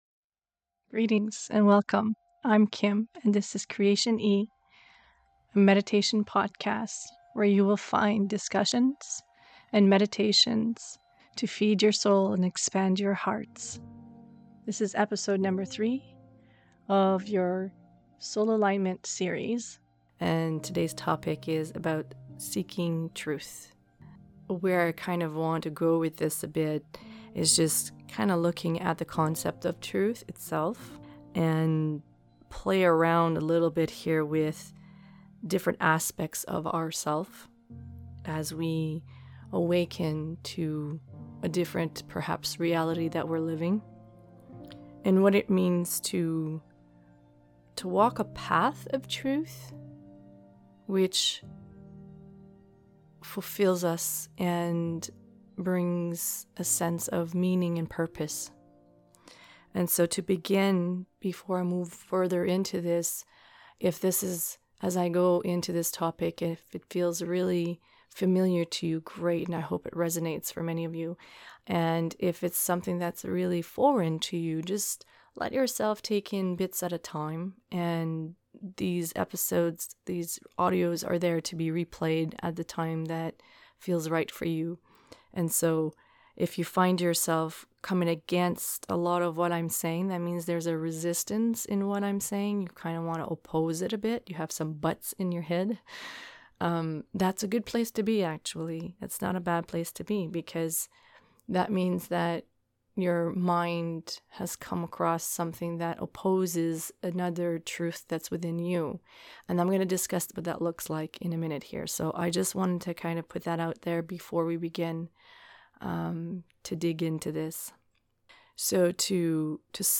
Meditation begins 19:30 (time stamp) &nbsp